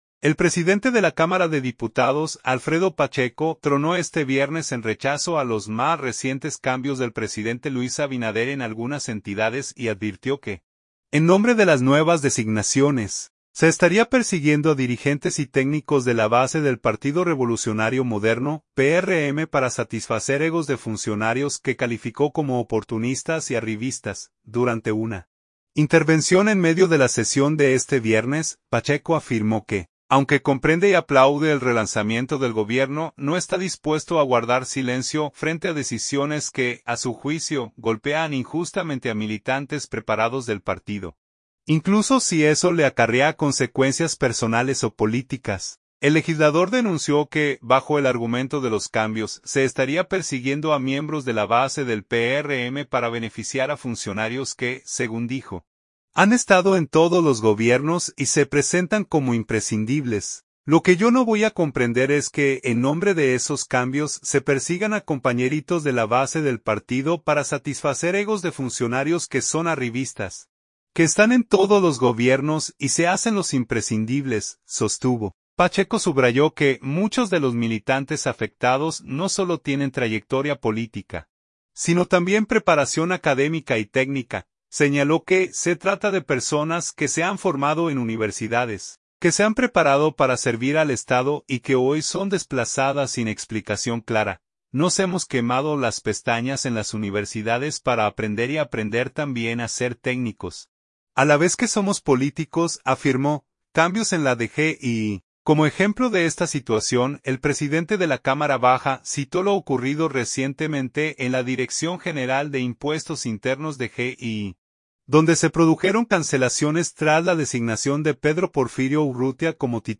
Durante una intervención en medio de la sesión de este viernes, Pacheco afirmó que, aunque comprende y aplaude el relanzamiento del Gobierno, no está dispuesto a guardar silencio frente a decisiones que, a su juicio, "golpean injustamente" a militantes preparados del partido, incluso si eso le acarrea consecuencias personales o políticas.